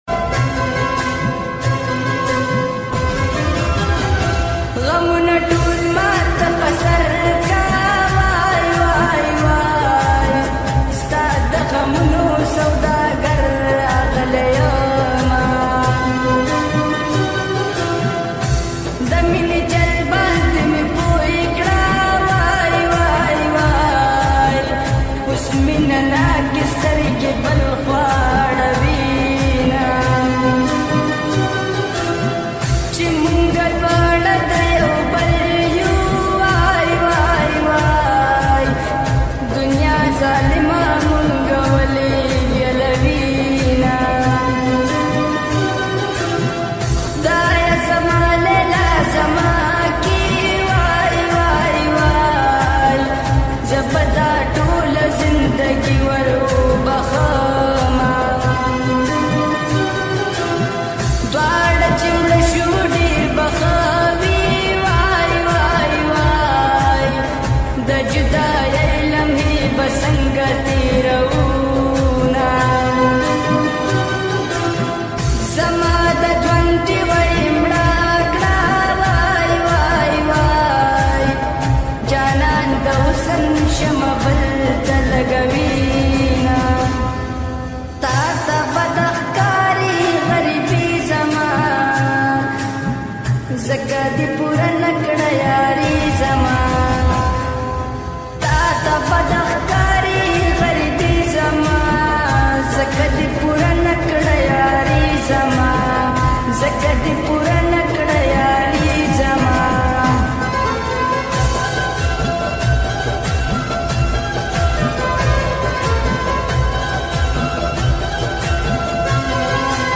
pashto full Sad song